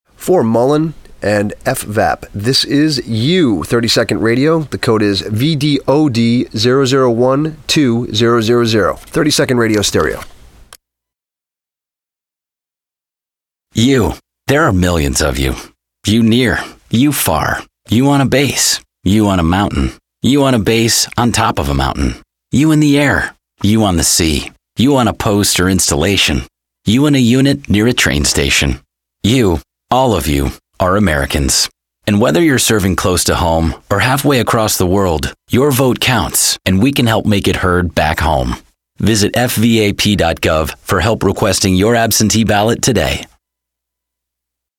Two versions of 30-second radio ads: You